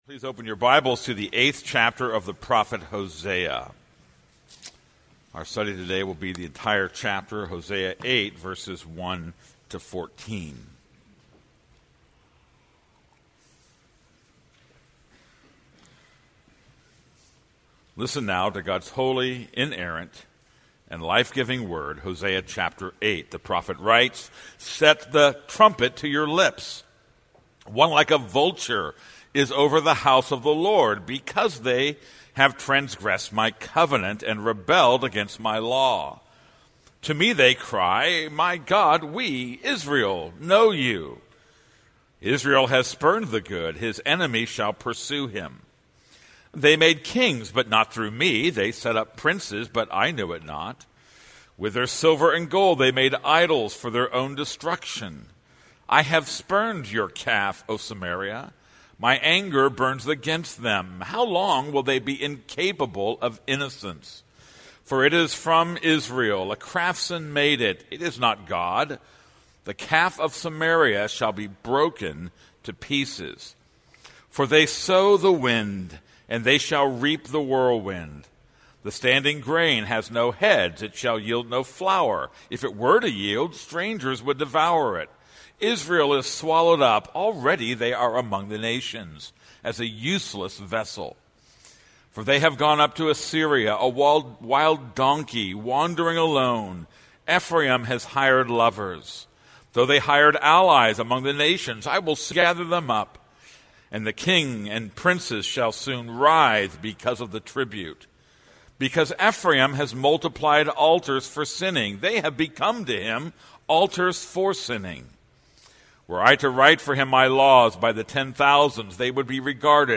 This is a sermon on Hosea 8:1-14.